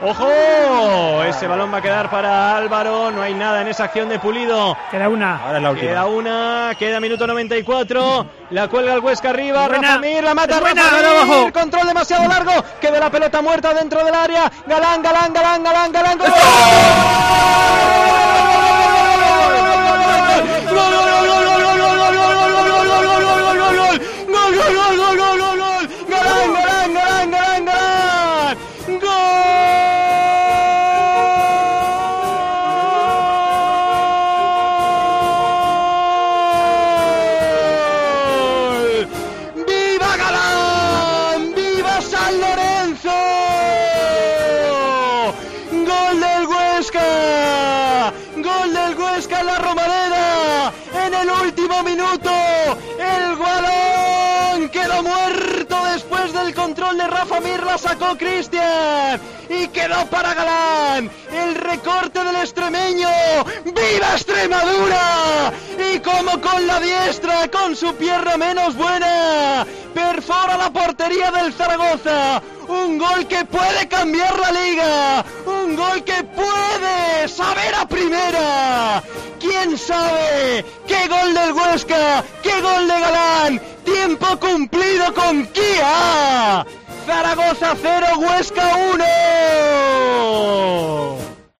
Deportes COPE en Huesca Narración